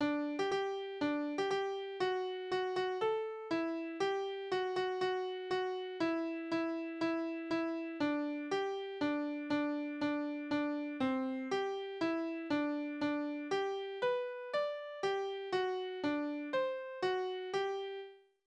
Kinderlieder: Bub und Spinne
Tonart: G-Dur
Taktart: 4/4
Tonumfang: große None